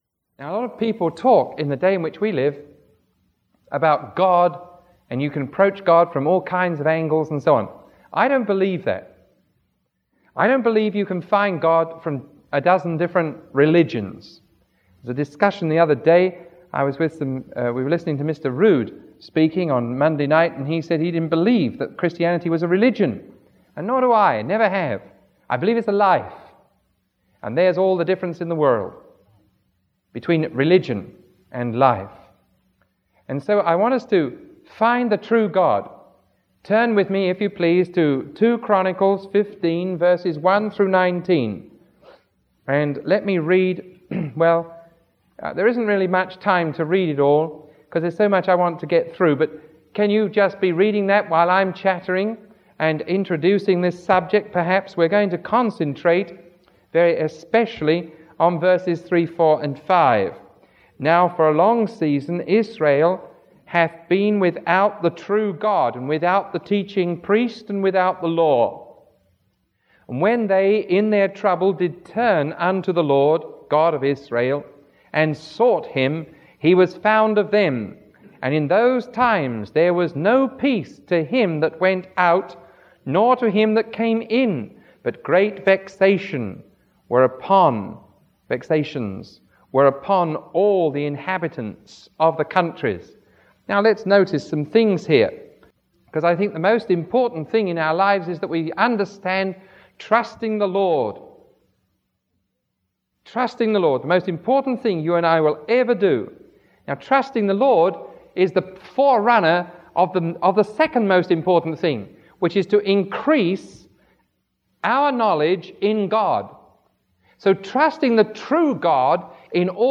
Sermon 0128A recorded on January 9